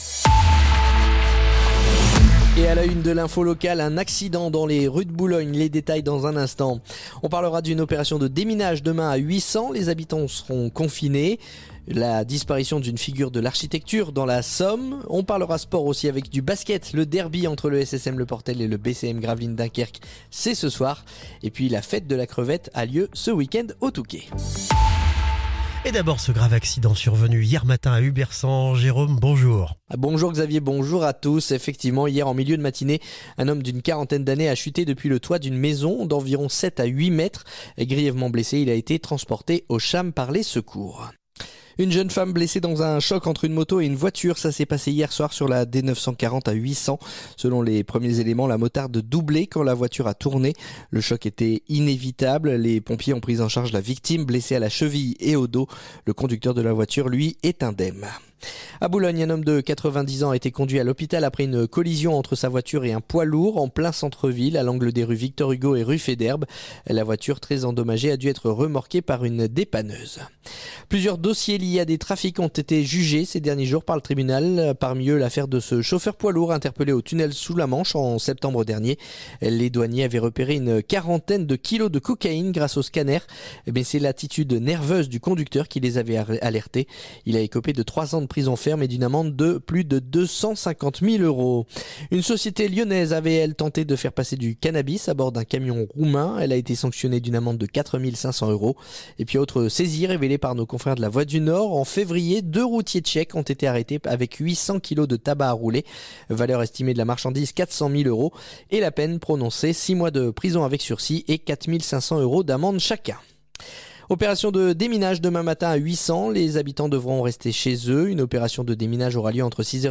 Le journal du mardi 7 octobre